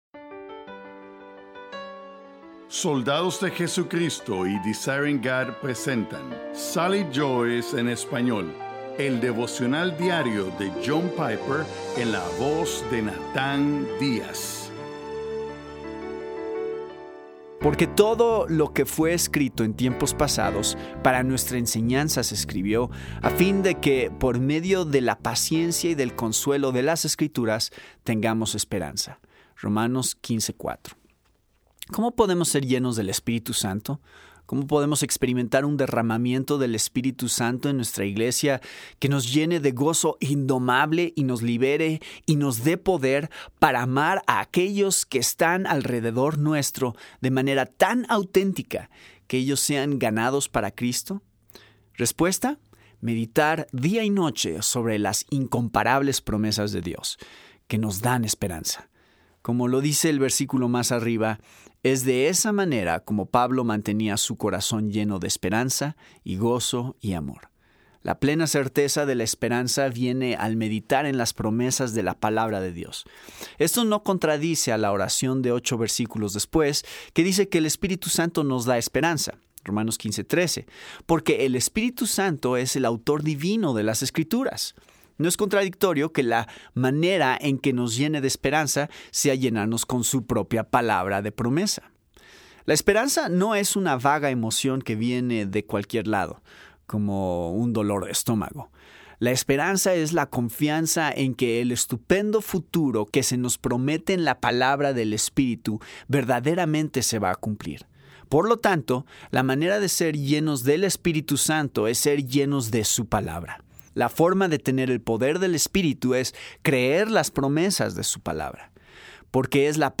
Devocional por John Piper